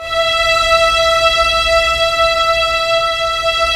Index of /90_sSampleCDs/Roland LCDP13 String Sections/STR_Symphonic/STR_Symph.+attak